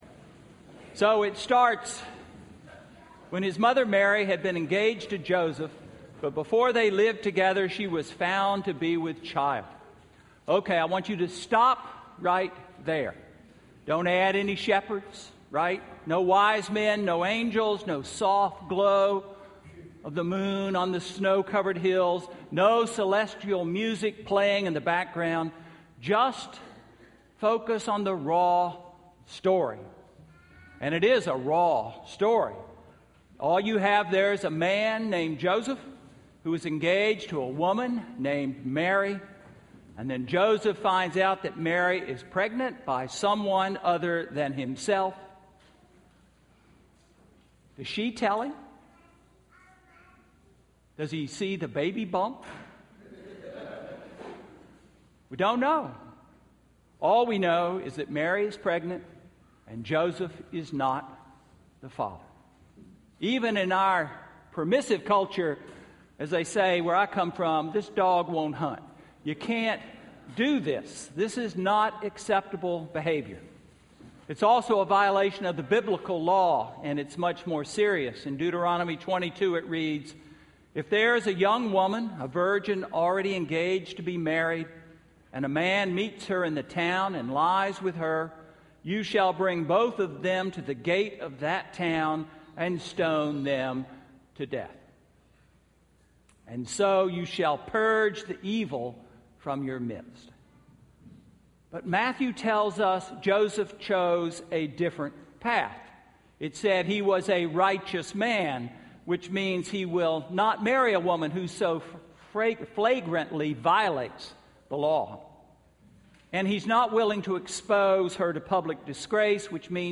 Sermon–December 22, 2013